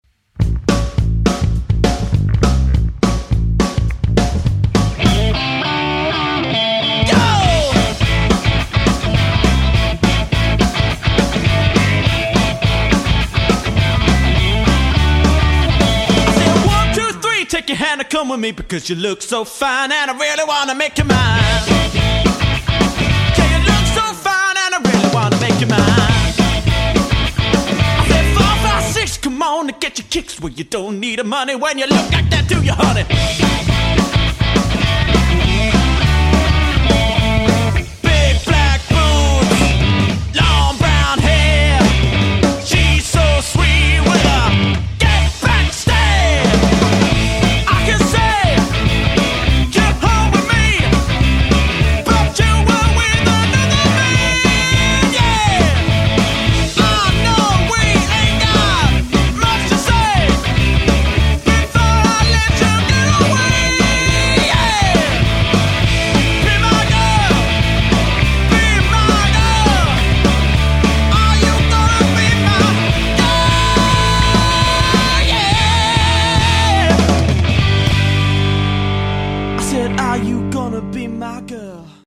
• 4-piece
Vocals / Guitar, Lead Guitar, Bass, Drums